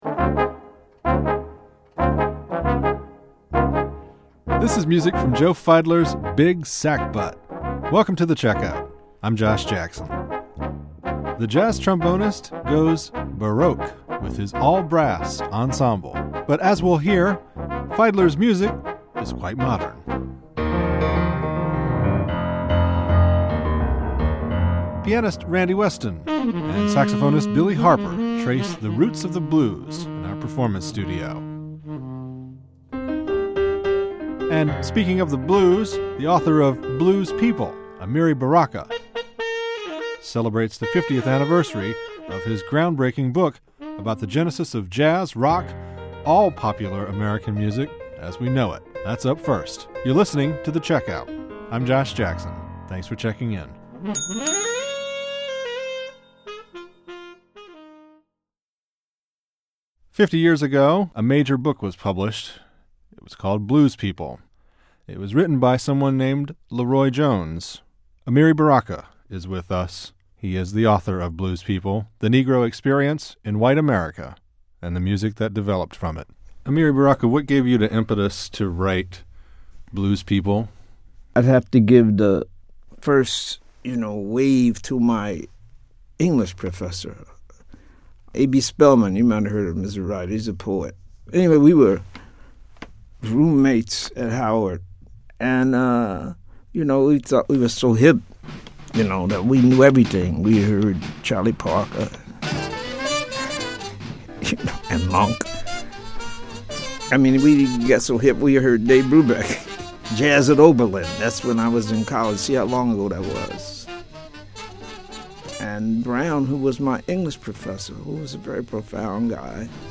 Live Jazz